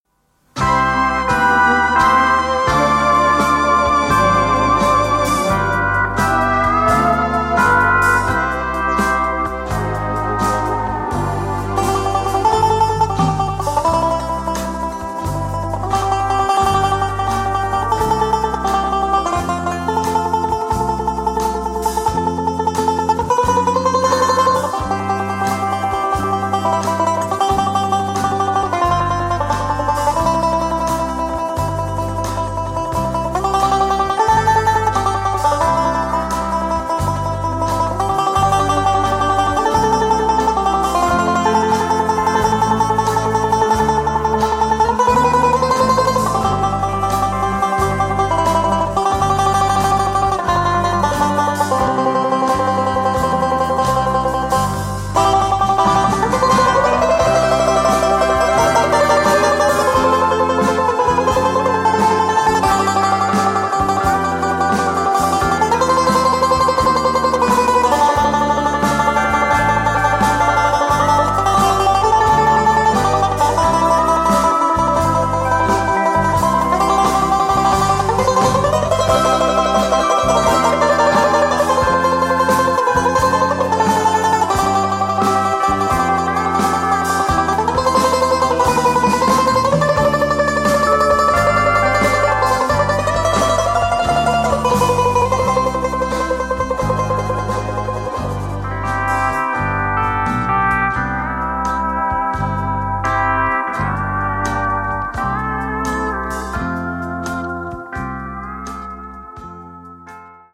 Swedish traditional melody and poem written by Carl Gustav Boberg - 1885
C                   F C Dm7            G7 C         (repeat all)
8-beat intro.